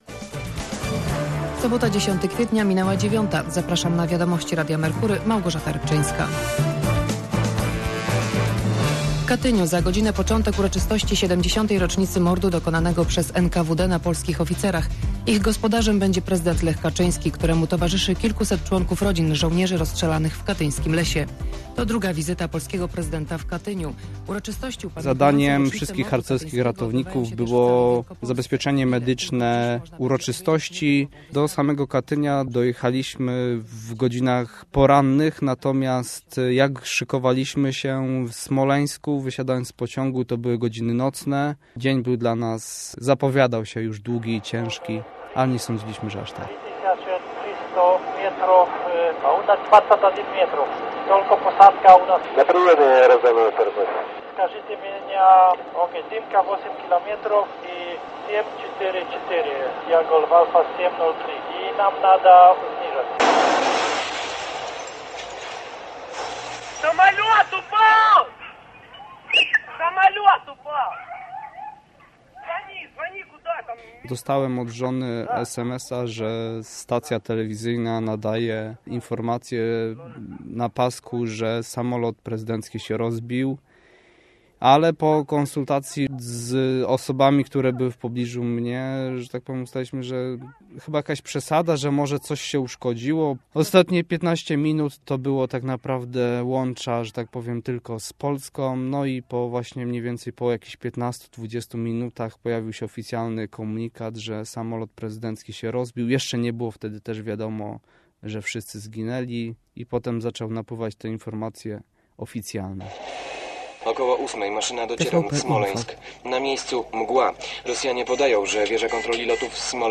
W sobotę - reportaż